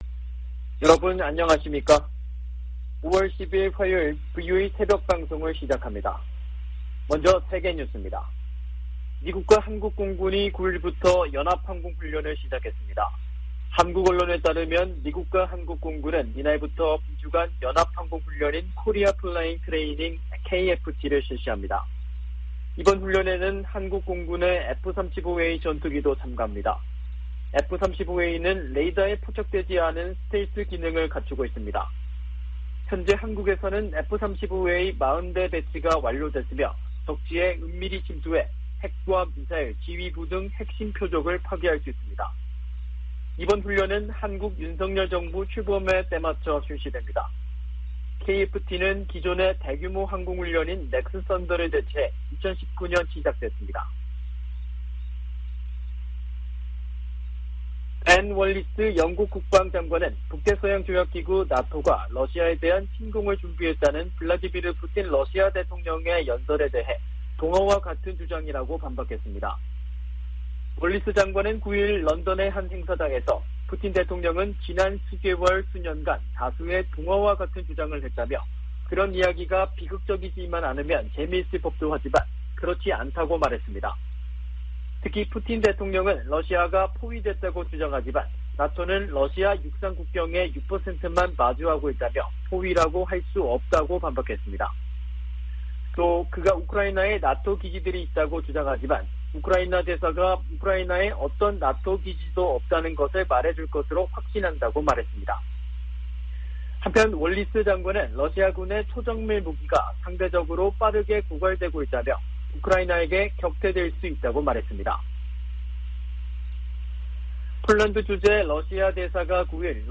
세계 뉴스와 함께 미국의 모든 것을 소개하는 '생방송 여기는 워싱턴입니다', 2022년 5월 10일 아침 방송입니다. '지구촌 오늘'에서는 러시아의 2차대전 전승절 기념행사 소식, '아메리카 나우'에서는 미 상원이 임신중절 권리에 관해 표결하는 이야기 전해드립니다.